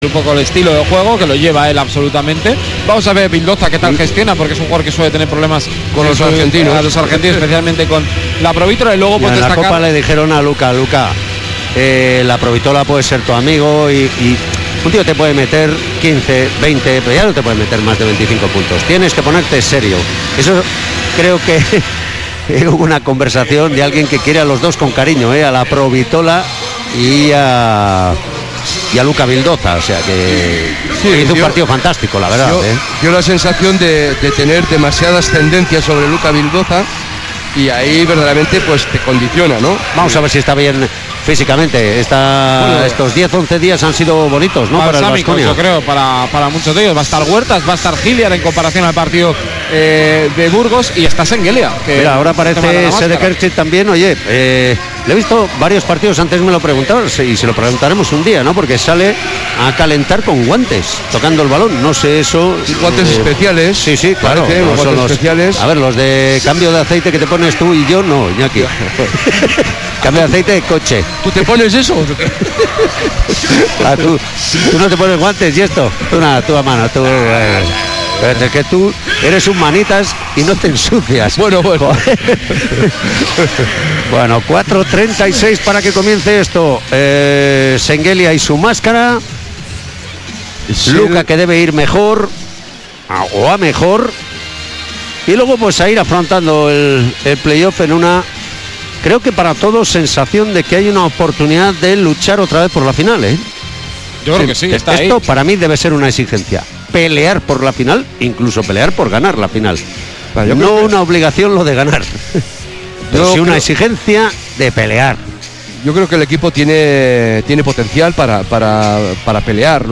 Kirolbet Baskonia-Joventut jornada 33 liga ACB 2018-19 retransmisión Radio Vitoria